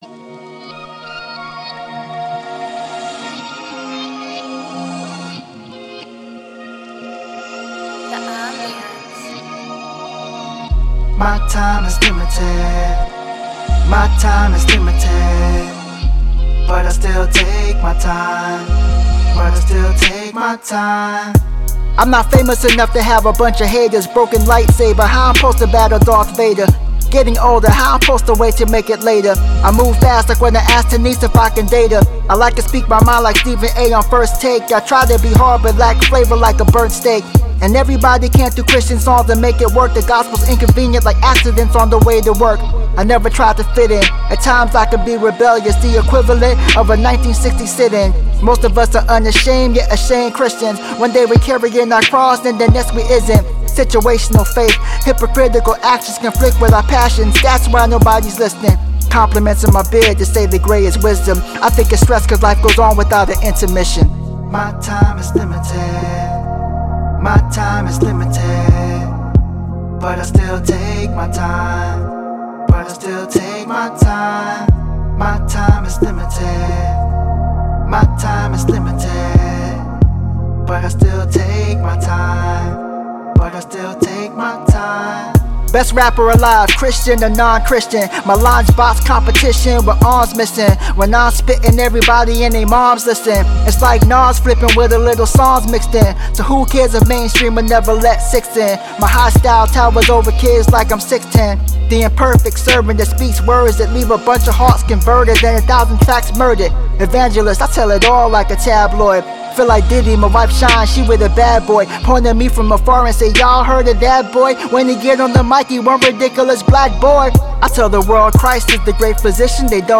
The song features a melodic backdrop and hypnotic chorus
Alternative Christian Hip-Hop